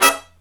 HIGH HIT06-L.wav